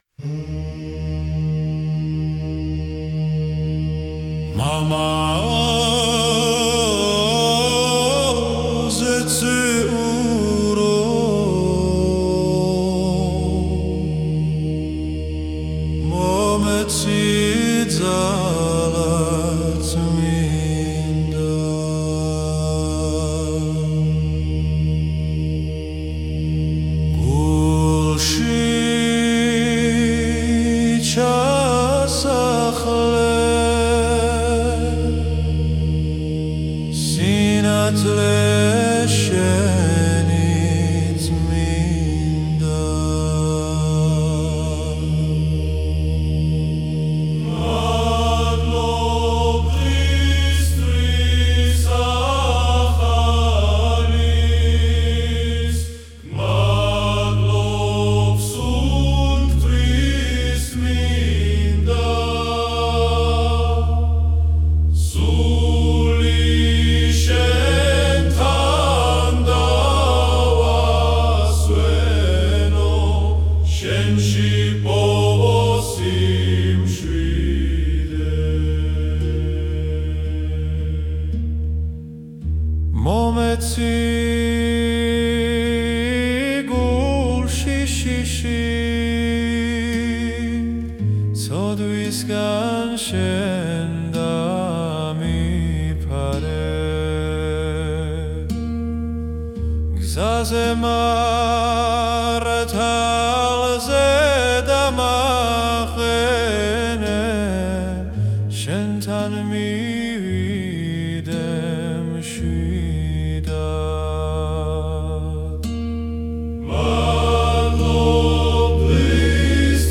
Грузинская акустическая молитва